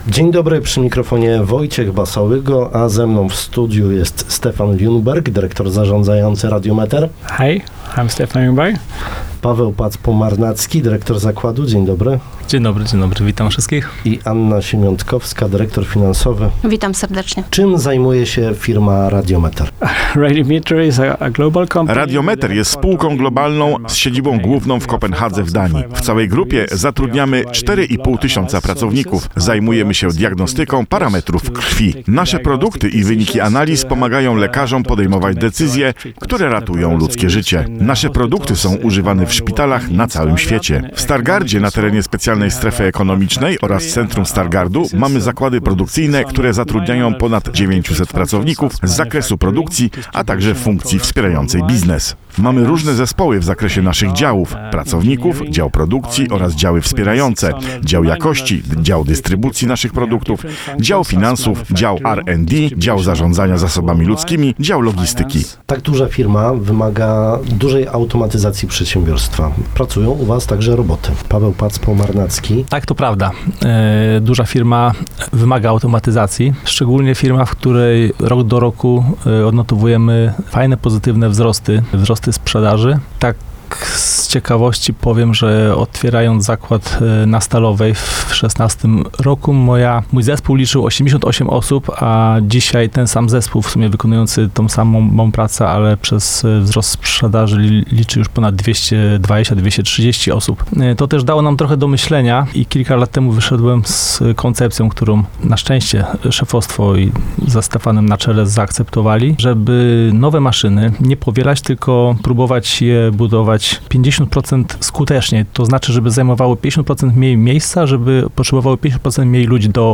Posłuchaj Rozmowy Dnia: